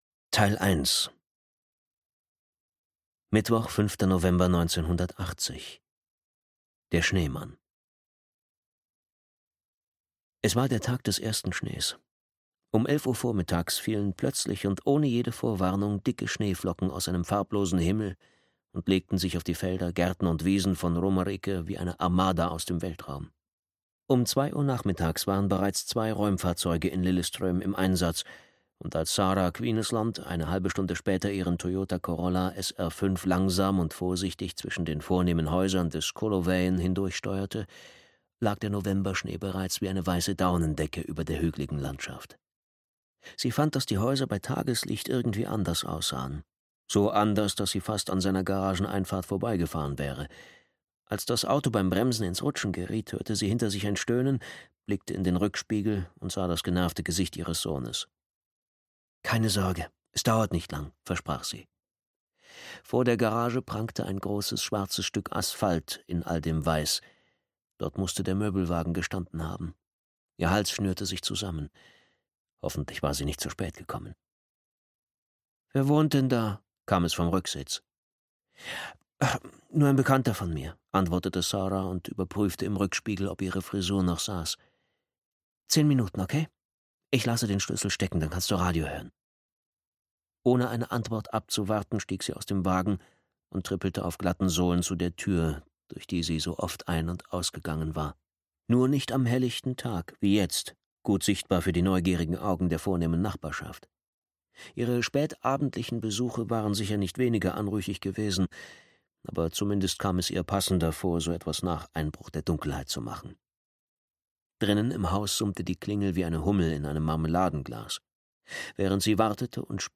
Schneemann (DE) audiokniha
Ukázka z knihy